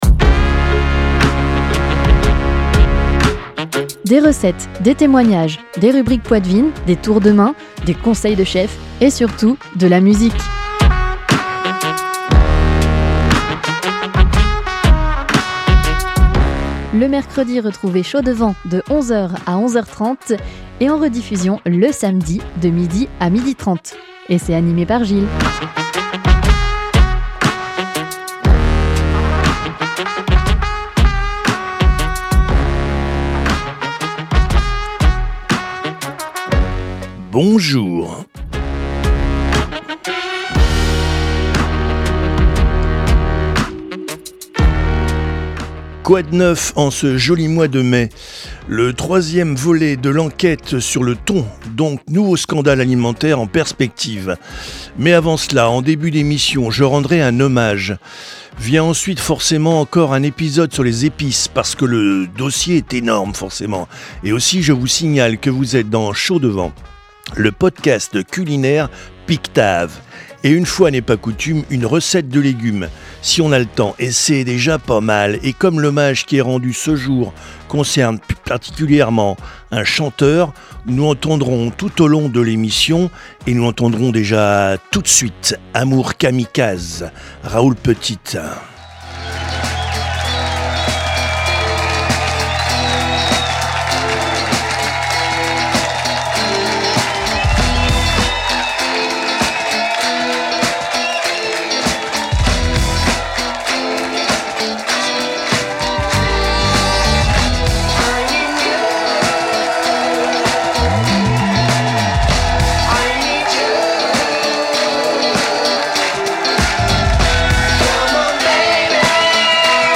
avec anecdotes, témoignages , rubriques , recettes , conseils de chef et musiques !